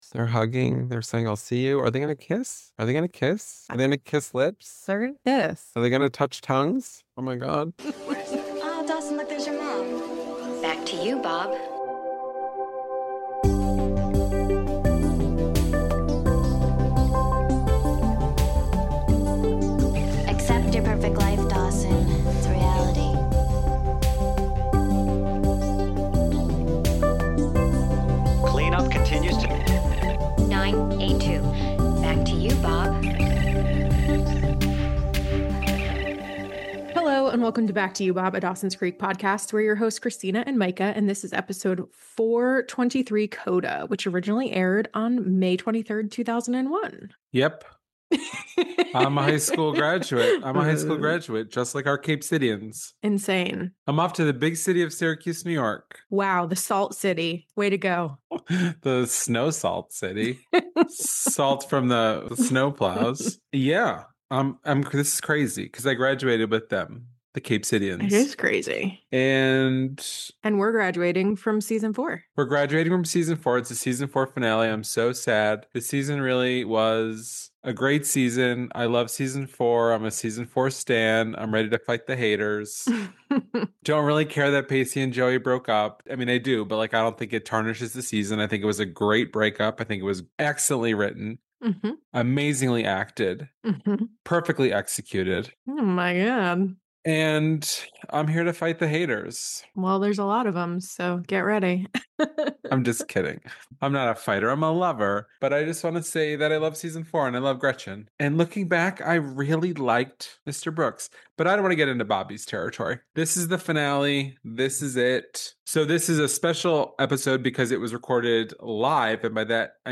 We’re marking the season four finale with a special live episode, as the series bids an emotional farewell to Capeside.